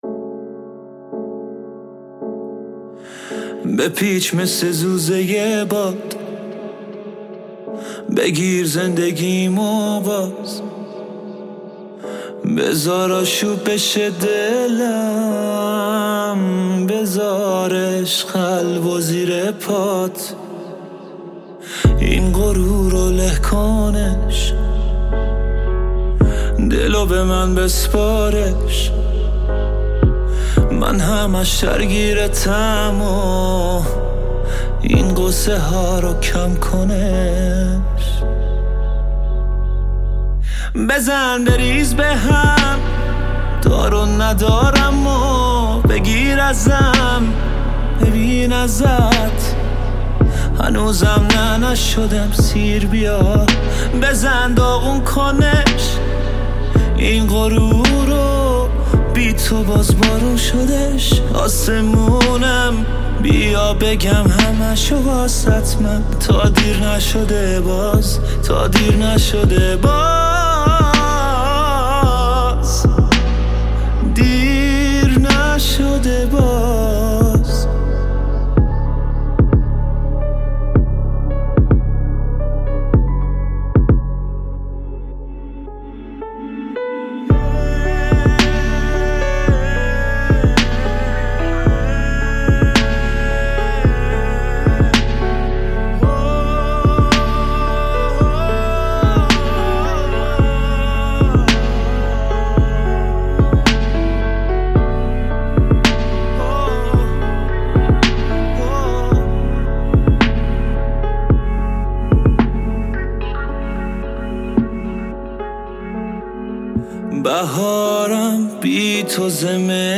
( ورژن آهسته )